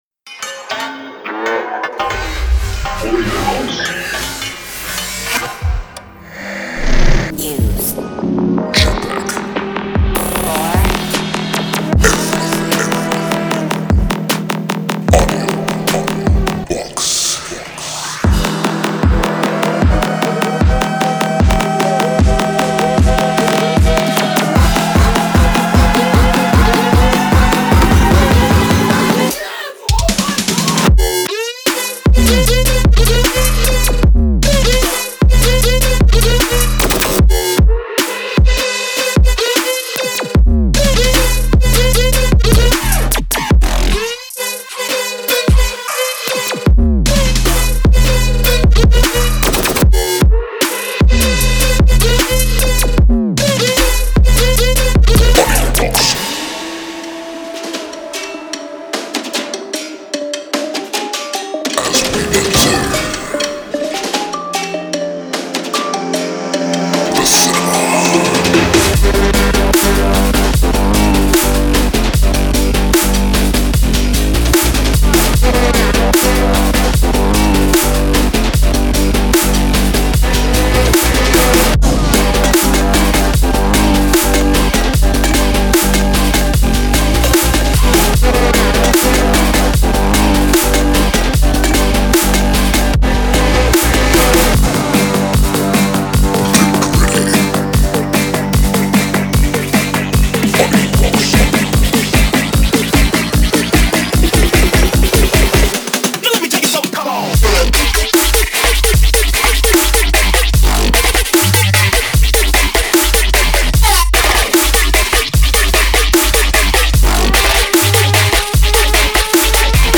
DubstepTrap
• 133个鼓循环
• 420个FX元素
• 81个合成器
• 30个陷阱人声